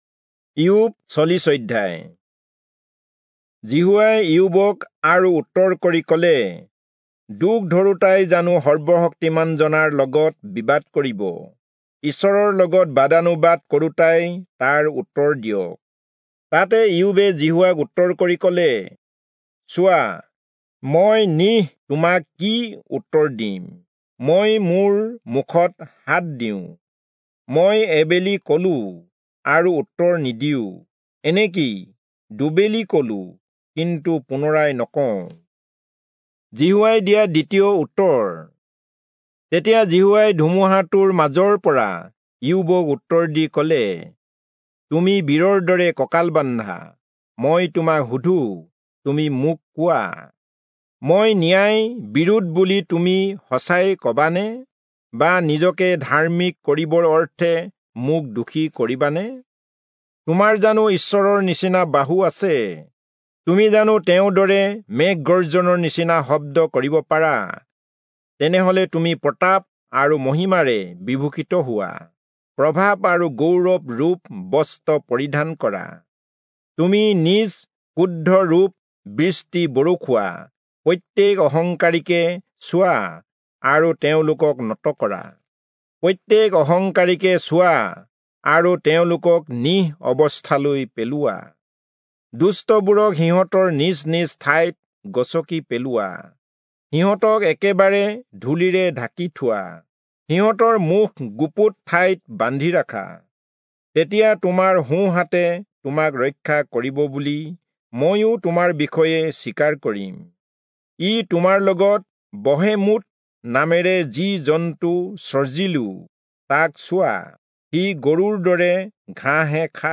Assamese Audio Bible - Job 25 in Guv bible version